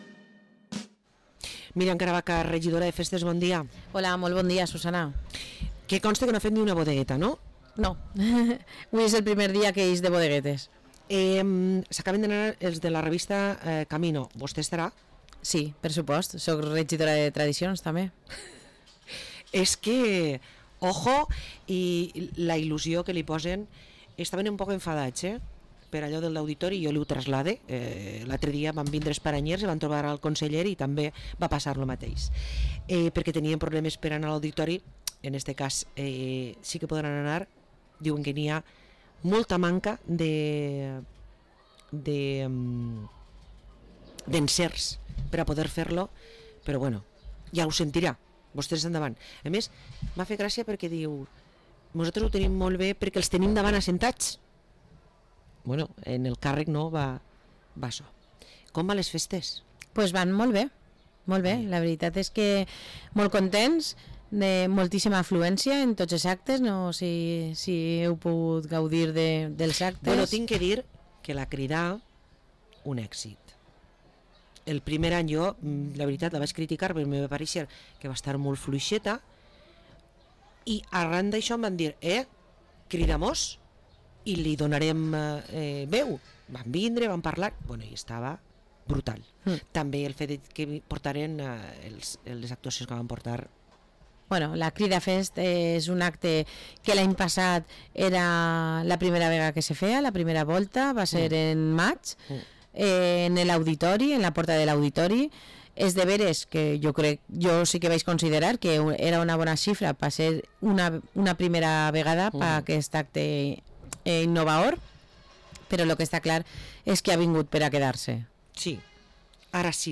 Parlem amb la regidora de festes de Vila-real, Miriam Caravaca